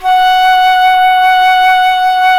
Index of /90_sSampleCDs/Roland LCDP04 Orchestral Winds/FLT_C Flutes 3-8/FLT_C Flt Act 4